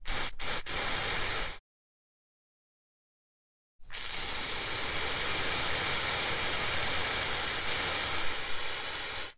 花园 " 2 驱虫剂 - 声音 - 淘声网 - 免费音效素材资源|视频游戏配乐下载
驱蚊喷涂